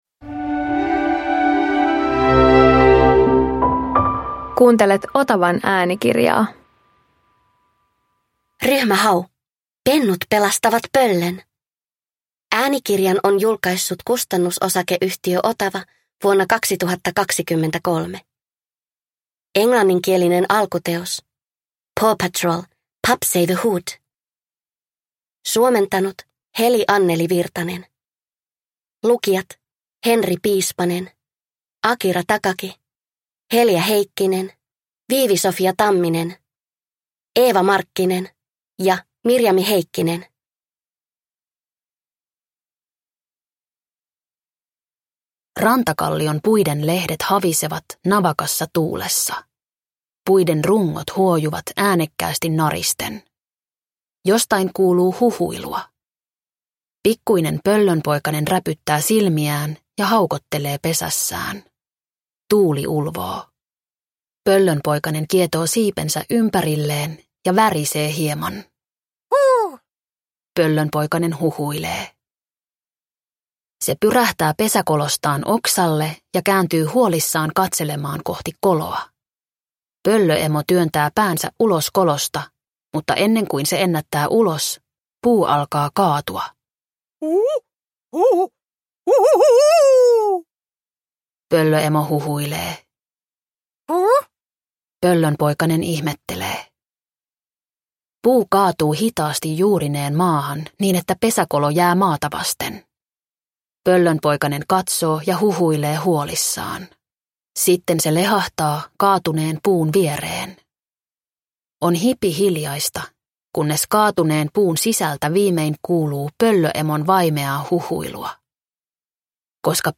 Ryhmä Hau - Pennut pelastavat pöllön – Ljudbok